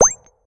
gain_xp_05.ogg